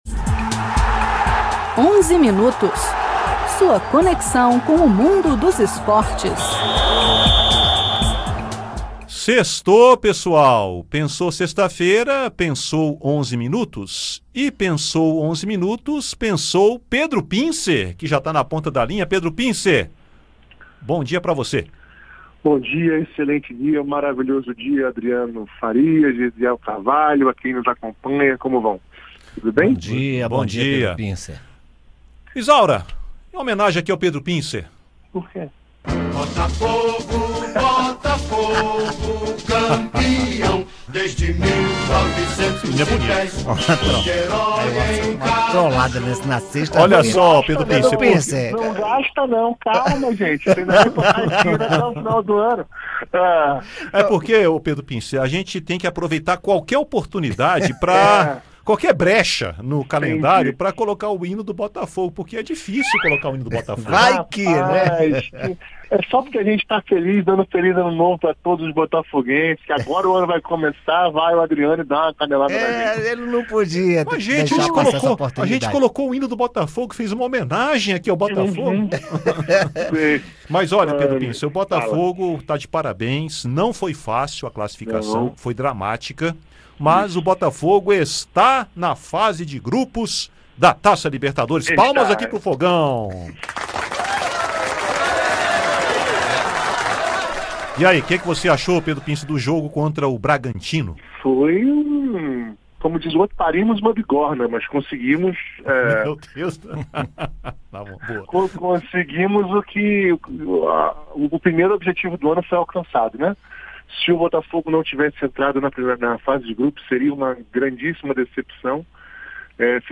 Uma audiência pública na Comissão de Esporte do Senado (CEsp) foi o destaque do Onze Minutos desta sexta-feira (15). Os senadores debateram a importância do Plano Nacional do Esporte para democratizar o acesso às práticas esportivas.